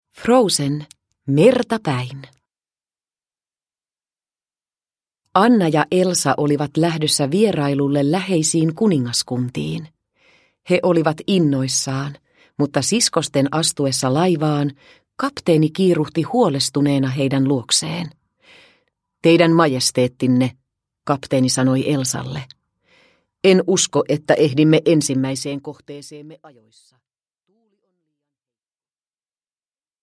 Frozen. Merta päin – Ljudbok – Laddas ner